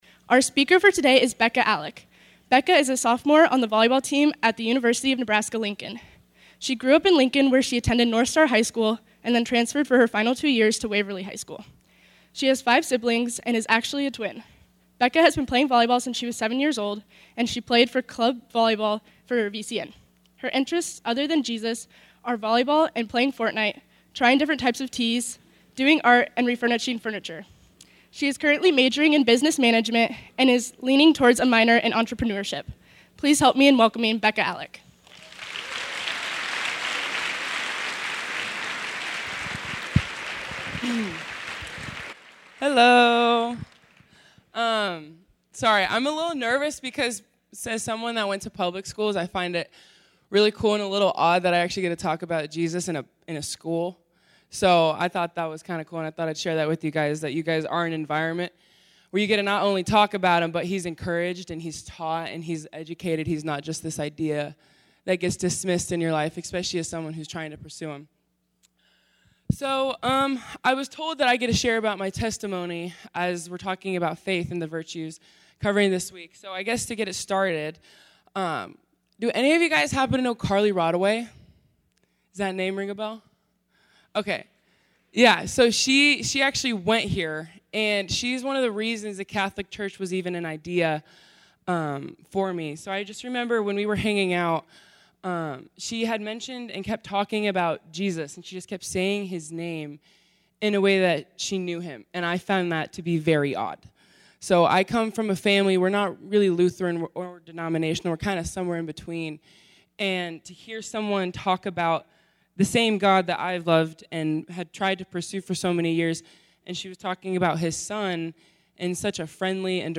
Advent Talk